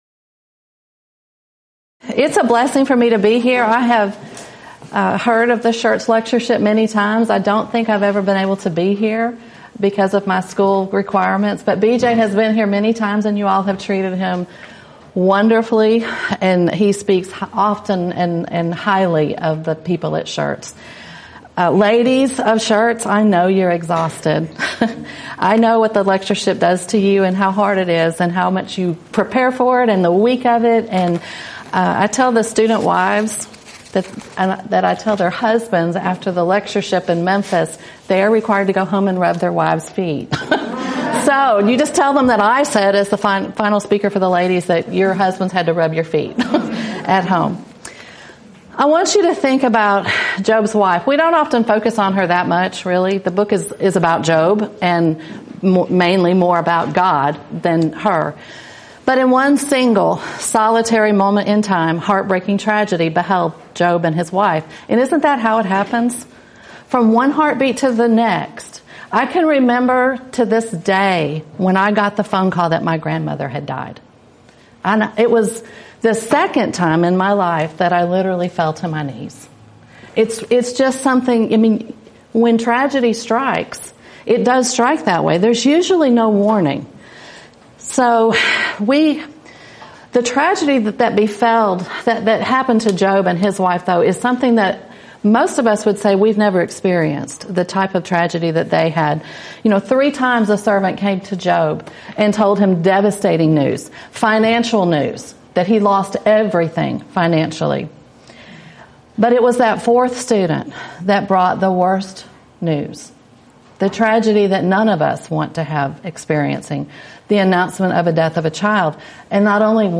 Event: 17th Annual Schertz Lectures
Ladies Sessions